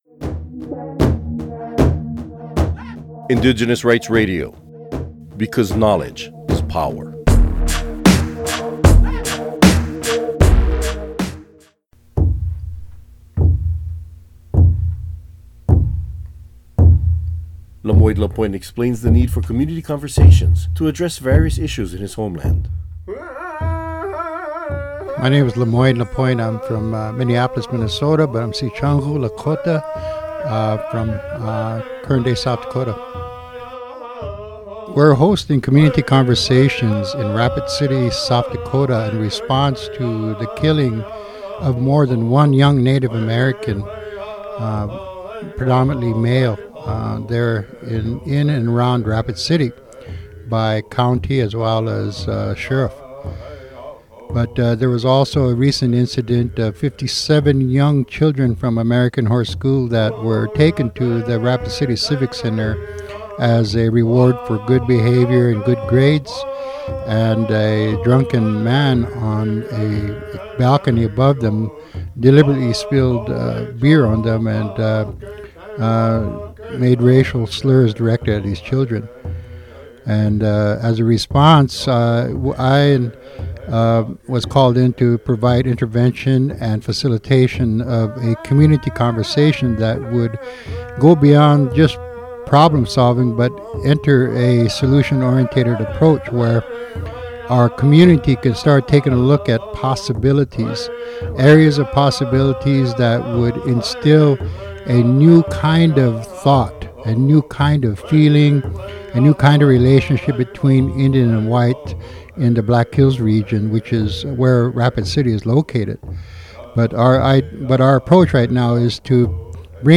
Recording Location: UNPFII 2015
Type: Interview
0kbps Stereo